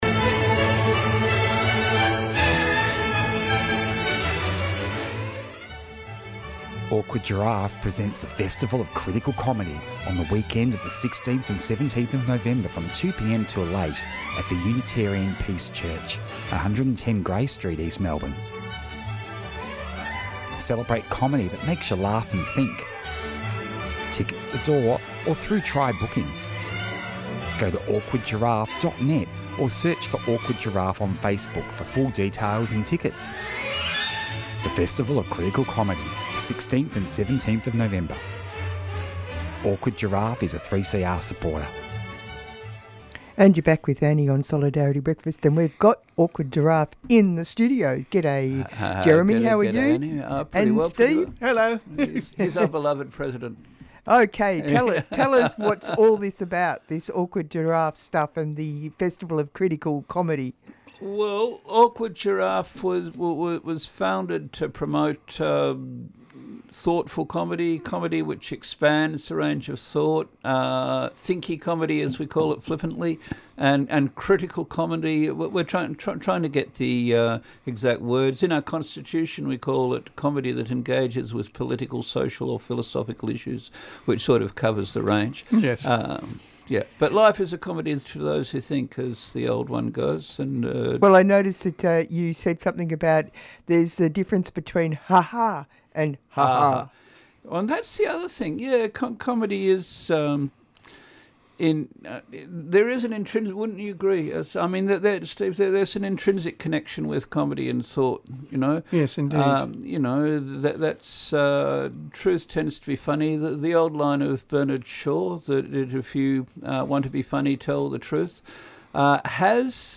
Save Dissent here II As we hear that the People's Blockade of the World's Biggest Coal Port November 22-24th and the larger Protestival event November 19-28 will go ahead despite the NSW Police taking Rising Tide to the Supreme Court to extend their policing powers we hear from an earlier April rally held in Melbourne calling for the defense of Dissent.